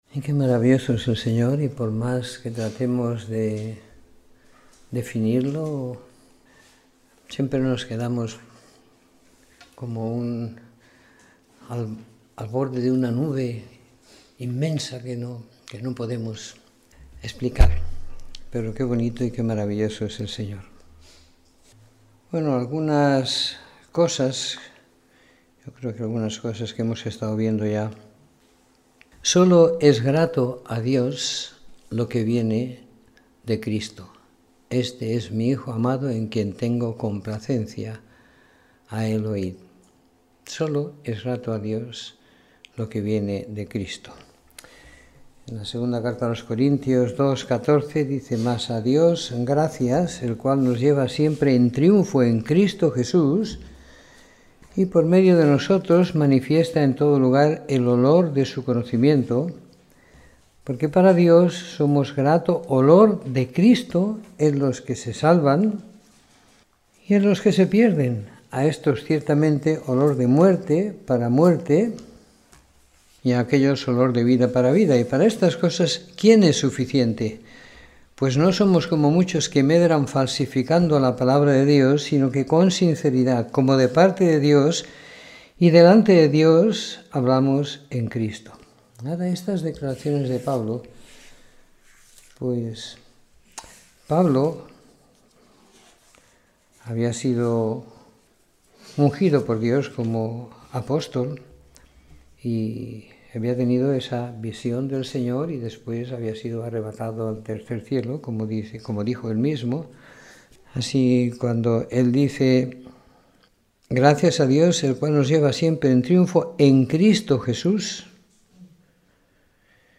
Domingo por la Tarde . 22 de Enero de 2017